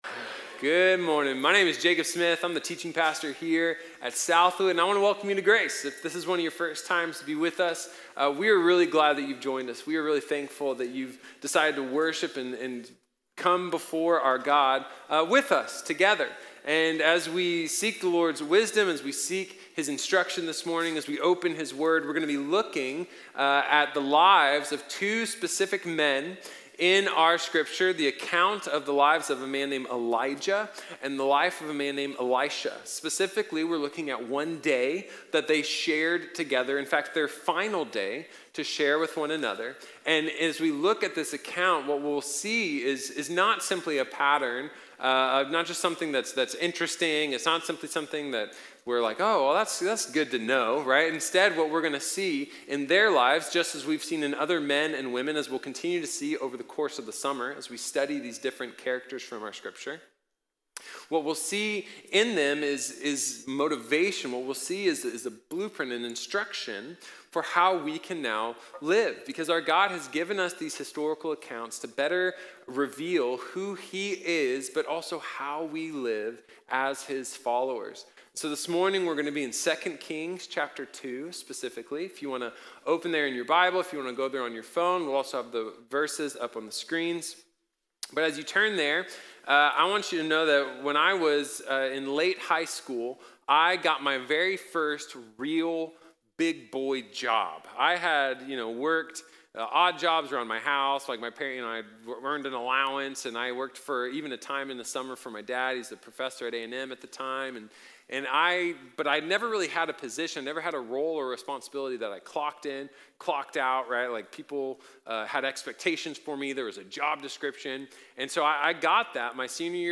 Elisha | Sermon | Grace Bible Church